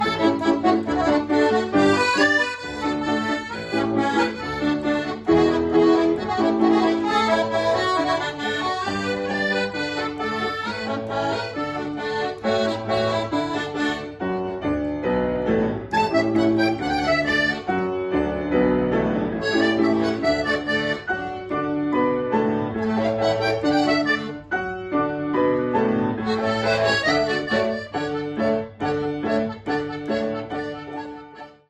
pianista
acordeonista